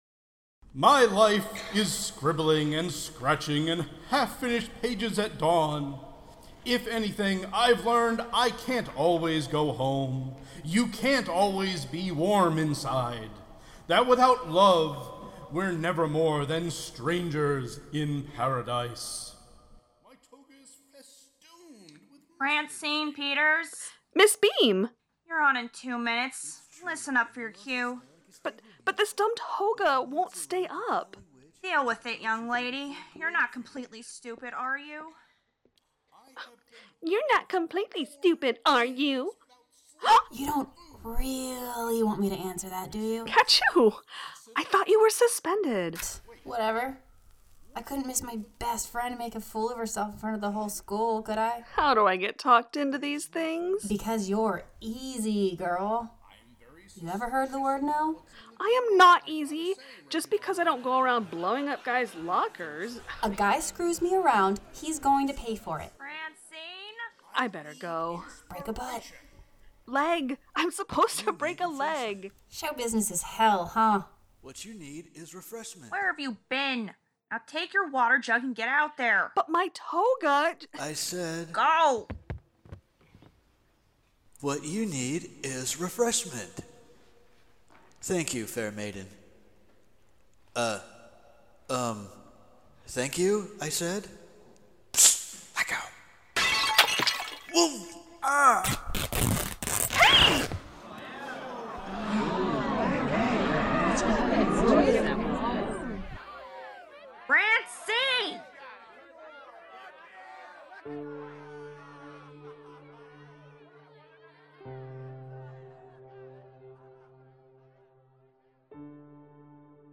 Welcome to the premier of STRANGERS IN PARADISE an audio drama based on the groundbreaking comic by Terry Moore and brought to life by the OCADECAGONAGON PLAYERS!!